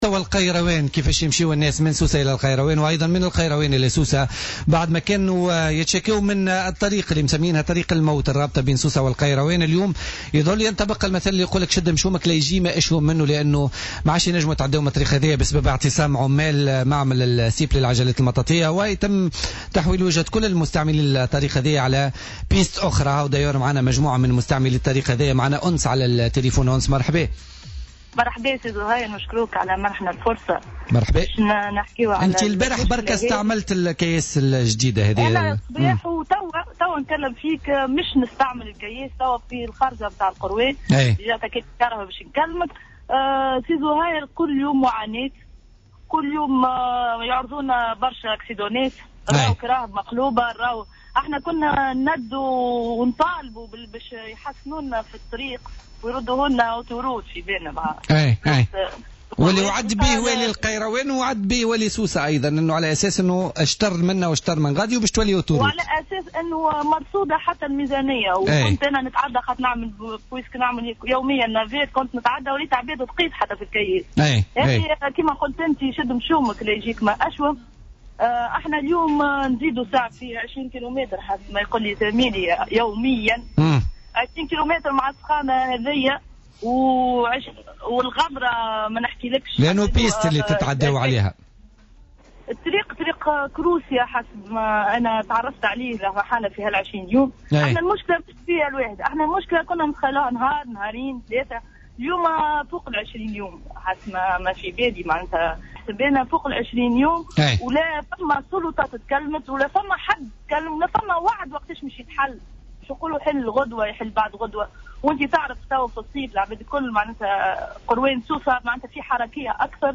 عبر عدد من أهالي القيروان من مستعملي الطريق الوطنية رقم 12 الرابطة بين سوسة والقيروان في مداخلات لهم في برنامج الحدث اليوم الإثنين 1 اوت 2016 عن استيائهم الشديد من تواصل غلق الطريق منذ 20 يوما من قبل عمال مصنع "ستيب" للعجلات المطاطية بسبب اعتصامهم للمطالبة بتسوية وضعياتهم المهنية.